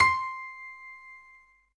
16 SY99 Piano C6.wav